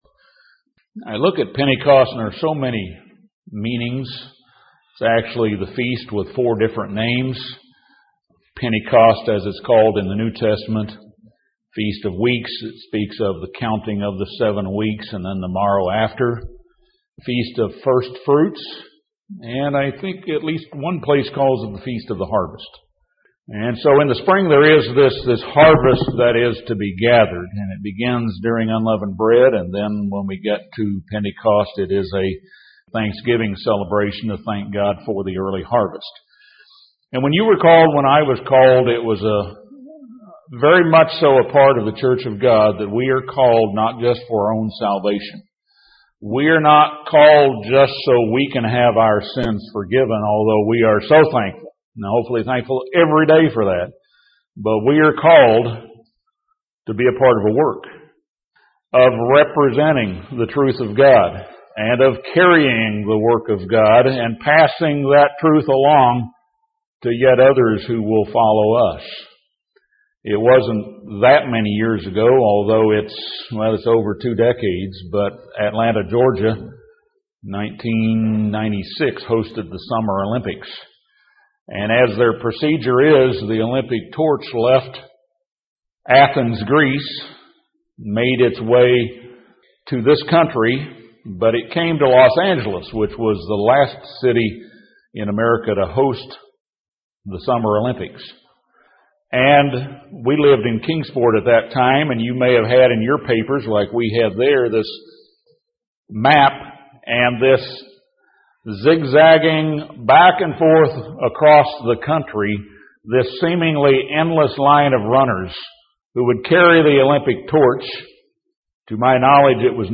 Sermons
Given in Birmingham, AL Gadsden, AL Huntsville, AL